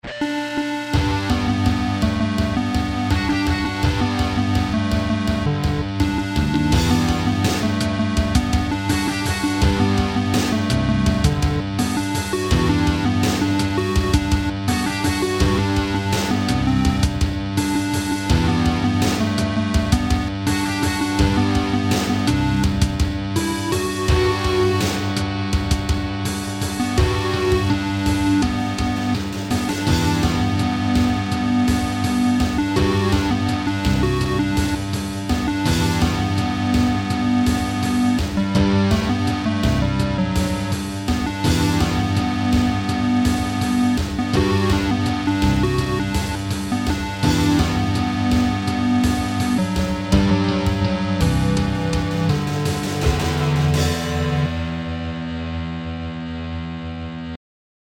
A MIDI composition